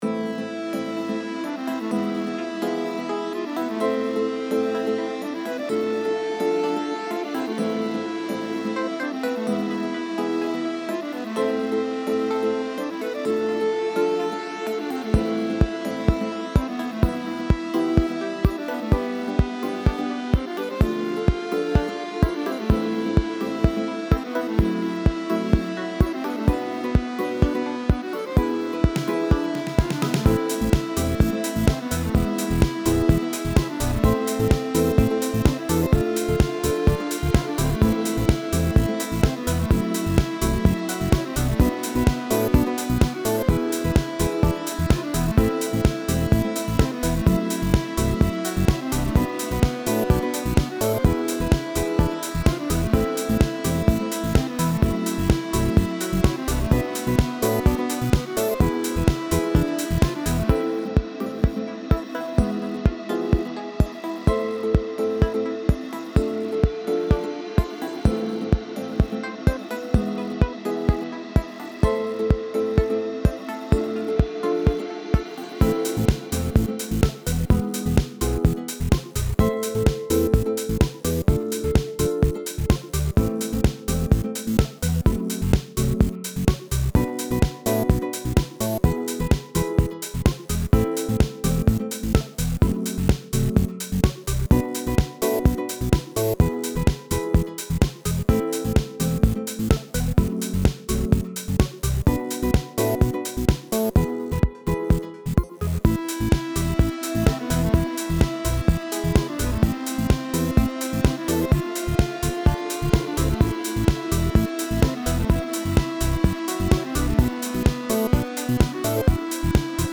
I used a few imported phrases, some example phrases, buit in chord progressions and some manual recording.
The acodrian sound on the parisian cafe track was hand drawn.